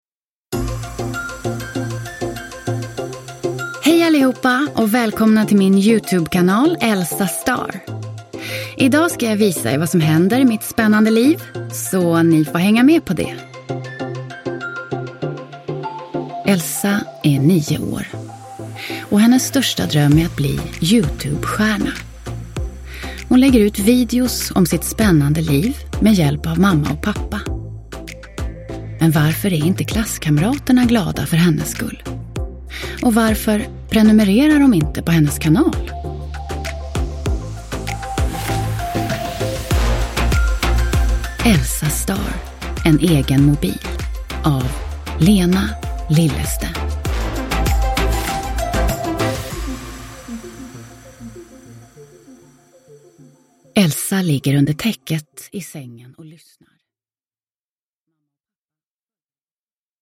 En egen mobil – Ljudbok – Laddas ner